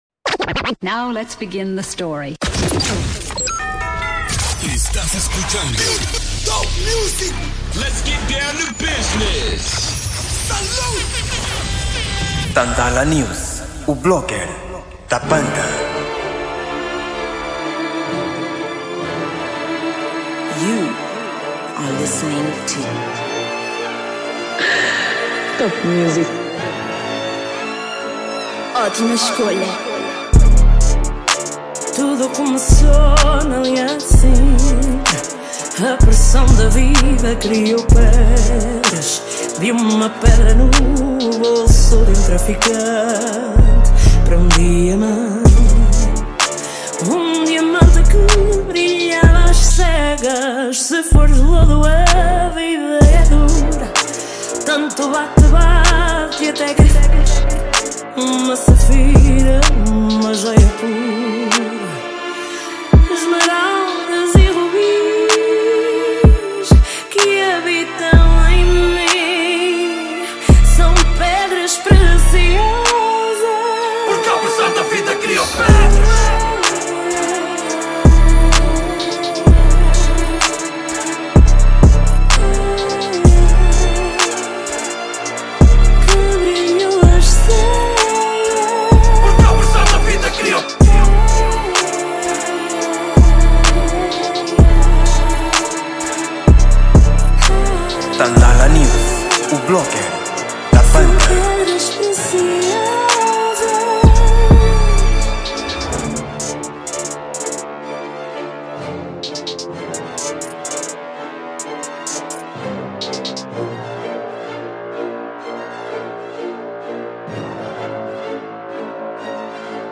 Gênero: Rap